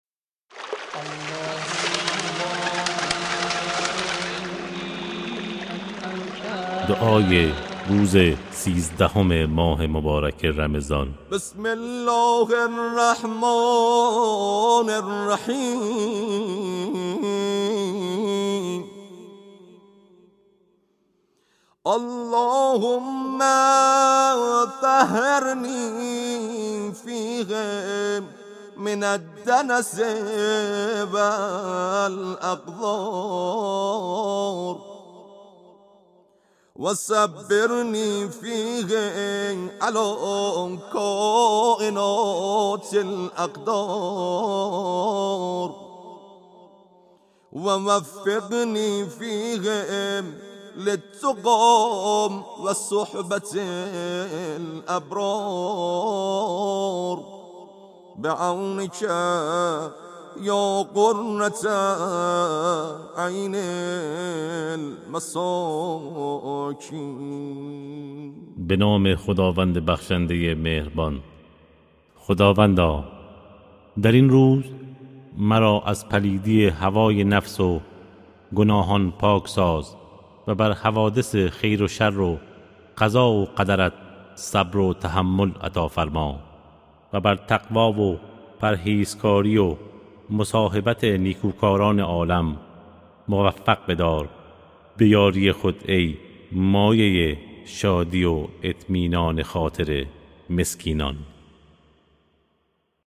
دعای روزهای ماه مبارک رمضان